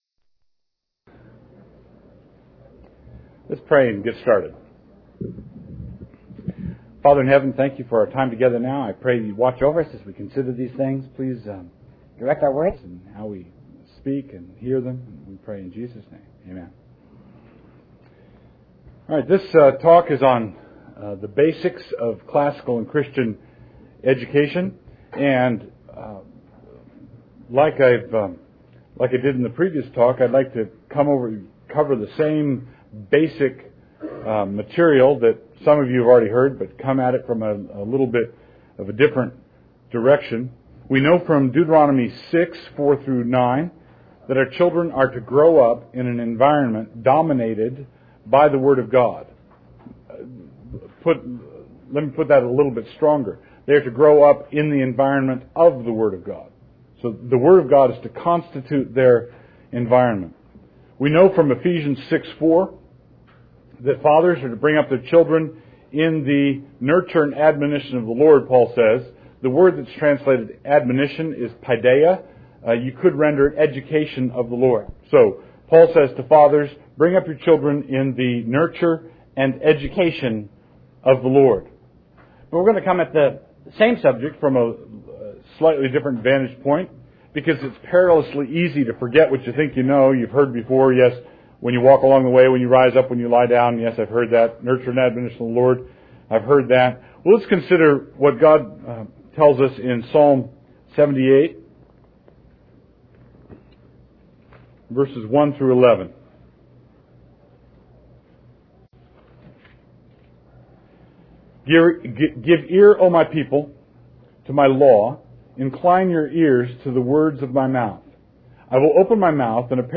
2004 Workshop Talk | 1:03:32 | Culture & Faith